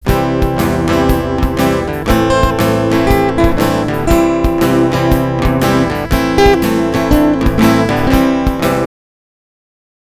Guitar 1:
Guitar 2:
Here is what it sounds like together :